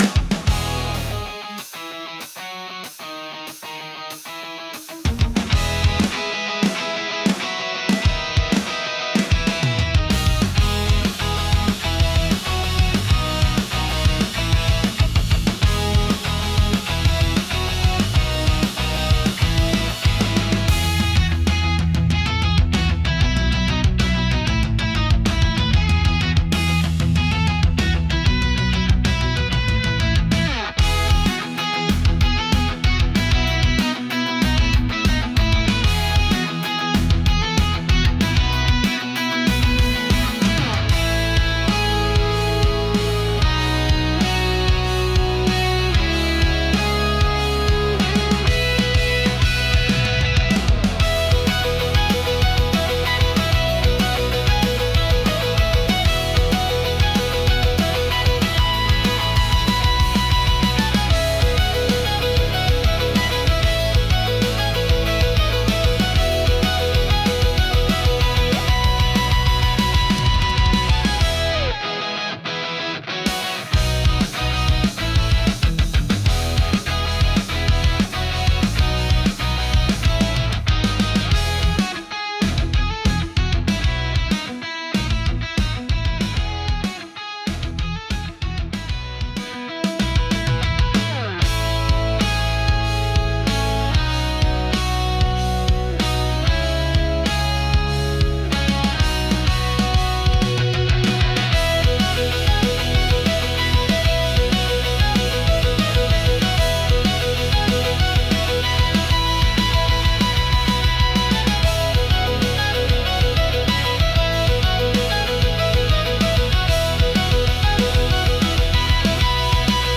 Genre: Pop Punk Mood: High Energy Editor's Choice